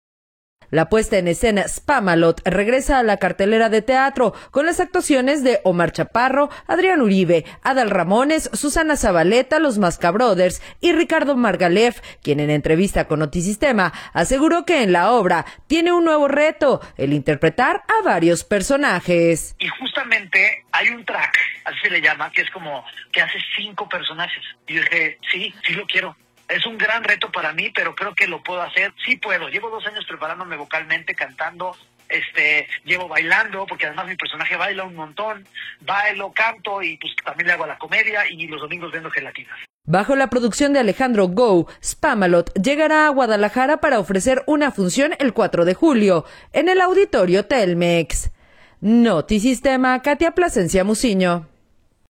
La puesta en escena Spamalot regresa a la cartelera de teatro con las actuaciones de Omar Chaparro, Adrián Uribe, Adal Ramones, Susana Zabaleta, Los Mascabrothers y Ricardo Margaleff, quien en entrevista con Notisistema, aseguró que en la obra tiene un nuevo reto, el interpretar a varios personajes.